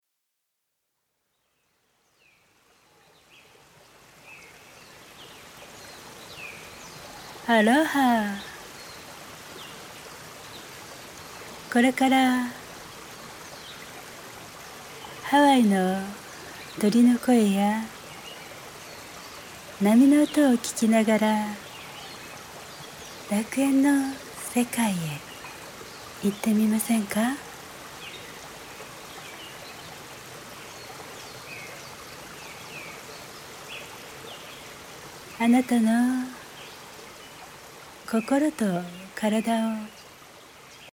柔らかい声で「さあ、息を吐きましょう」というように潜在意識に呼び掛けてくれるので、